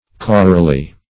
Search Result for " chorally" : Wordnet 3.0 ADVERB (1) 1. by a chorus ; - Example: "chorally accompanied" The Collaborative International Dictionary of English v.0.48: Chorally \Cho"ral*ly\, adv.